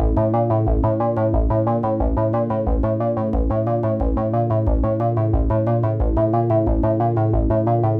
Index of /musicradar/dystopian-drone-samples/Droney Arps/90bpm
DD_DroneyArp4_90-A.wav